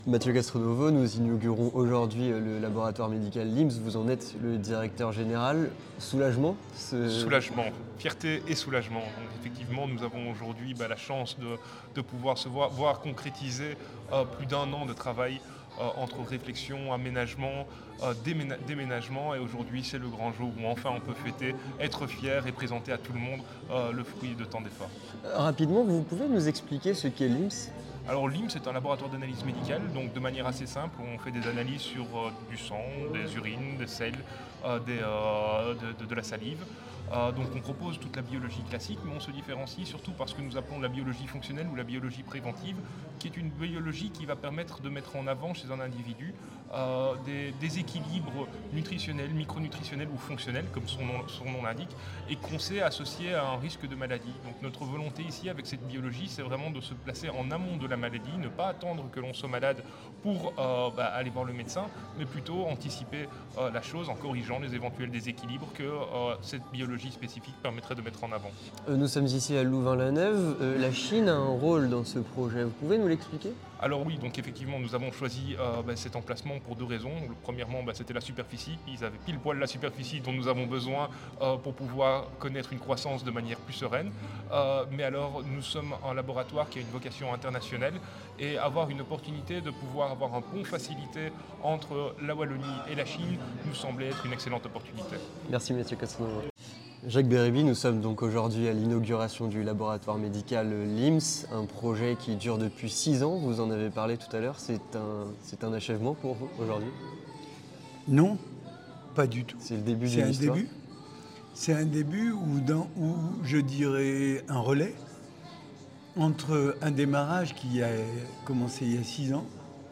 Vue de Belgique - Reportage au centre d'analyses médicales LIMS